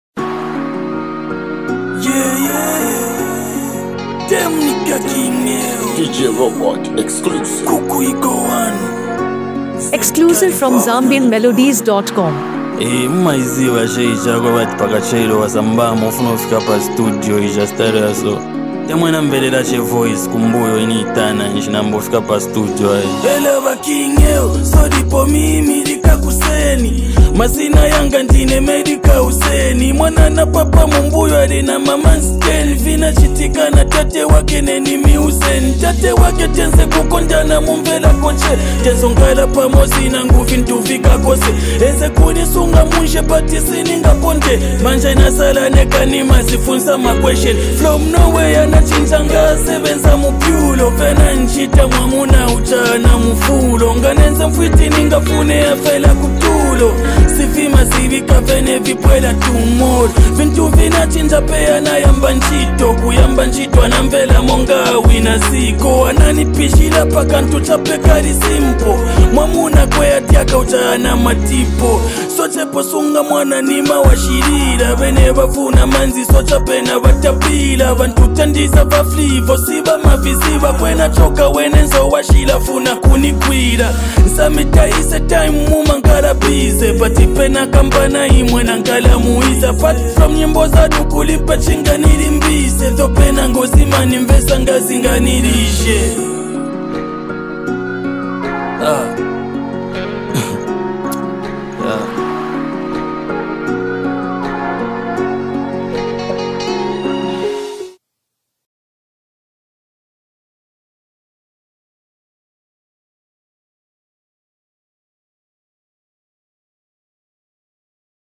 With heartfelt delivery and engaging wordplay